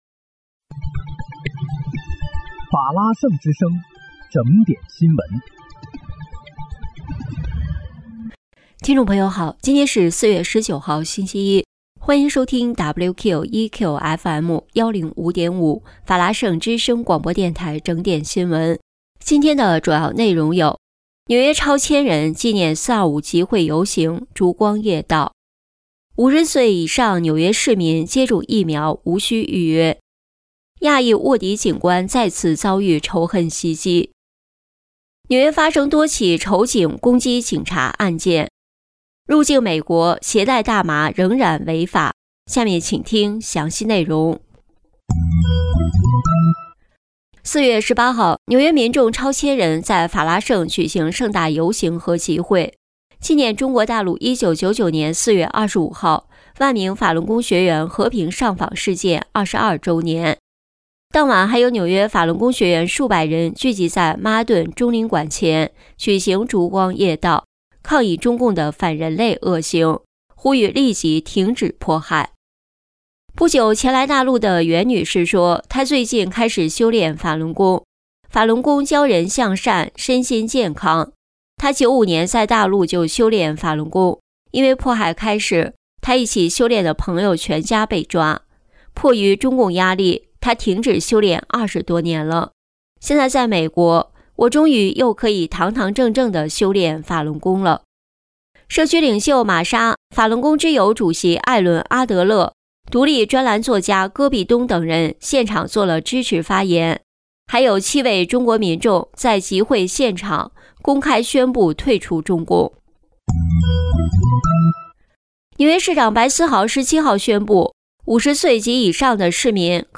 4月19日（星期一）纽约整点新闻
听众朋友您好！今天是4月19号，星期一，欢迎收听WQEQFM105.5法拉盛之声广播电台整点新闻。